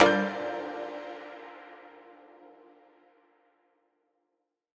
menu-freeplay-click.mp3